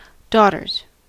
Ääntäminen
Ääntäminen US Haettu sana löytyi näillä lähdekielillä: englanti Käännöksiä ei löytynyt valitulle kohdekielelle. Daughters on sanan daughter monikko.